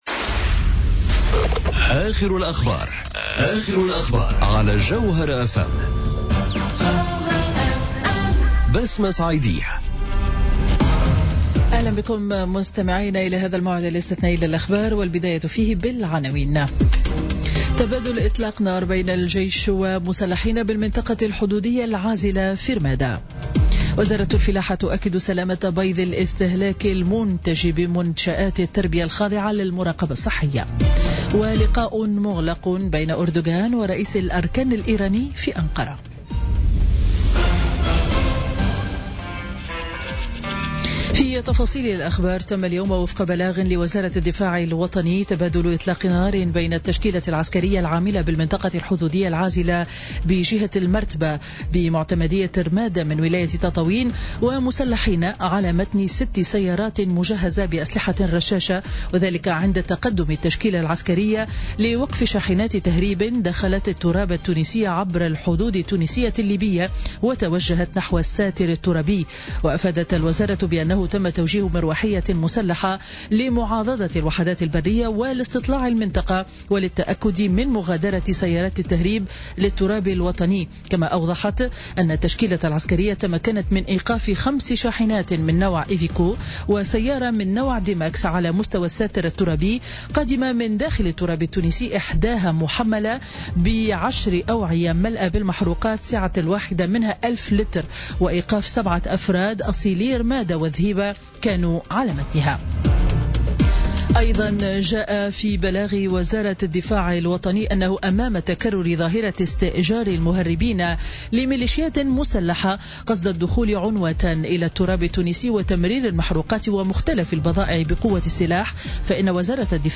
نشرة أخبار السابعة مساء ليوم الأربعاء 16 أوت 2017